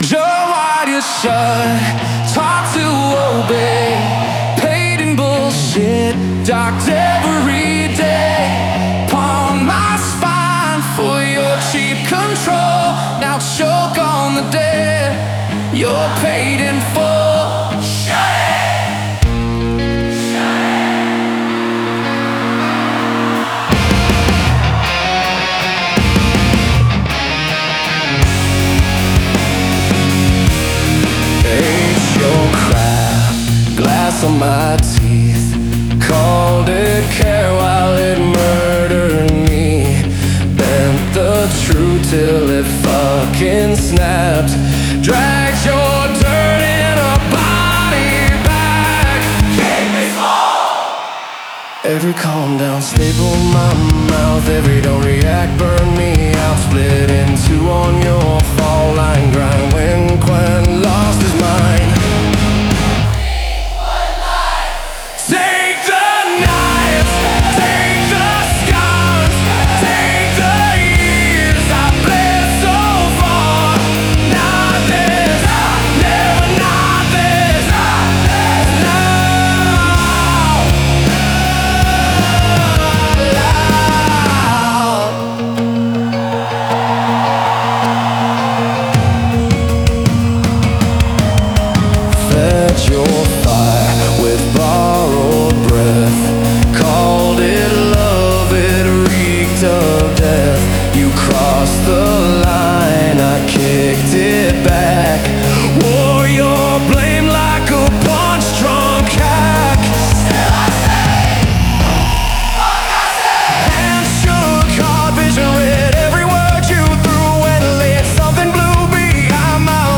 サビでは個人の声が合唱へと拡張され、孤独な抵抗が集団的な否定へと変わる。